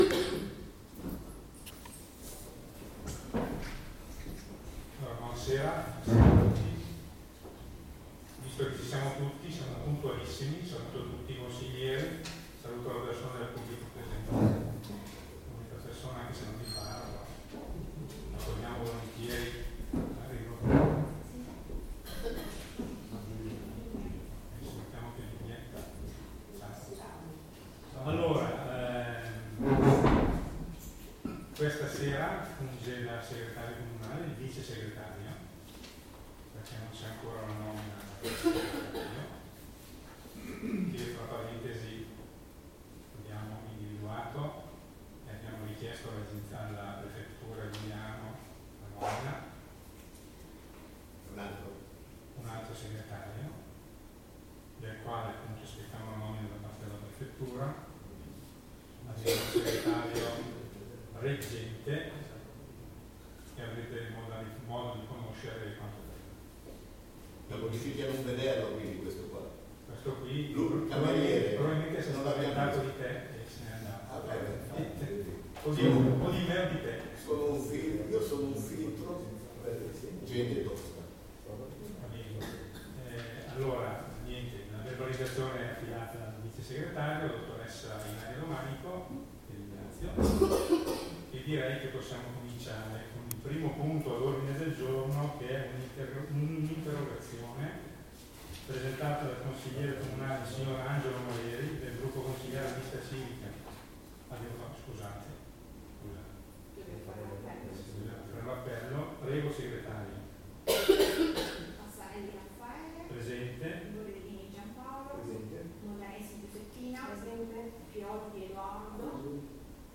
Registrazione seduta consiliare del 13.11.2023